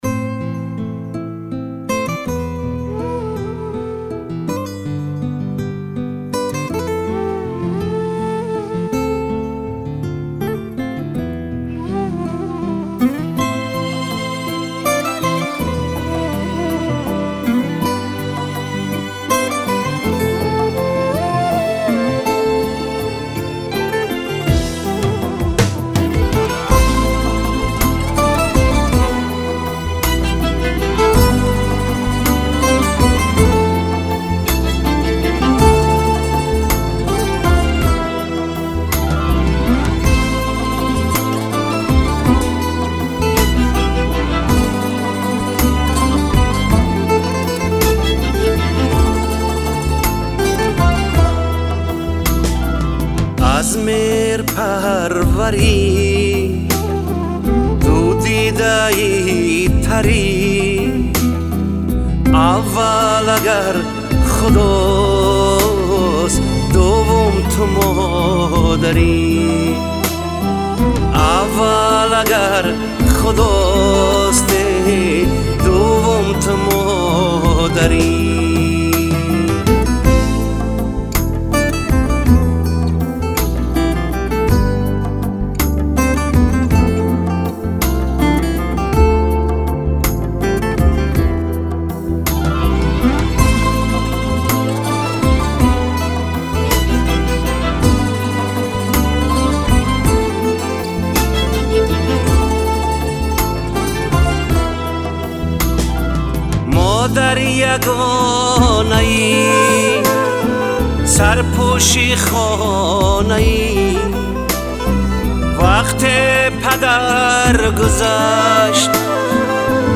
бо садои хонандаи тоҷик